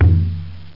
Conga Sound Effect
Download a high-quality conga sound effect.
conga-1.mp3